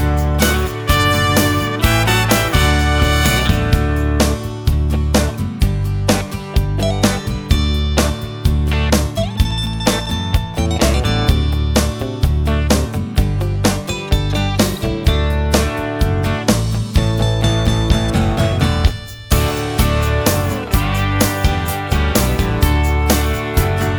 no Backing Vocals Country (Male) 2:59 Buy £1.50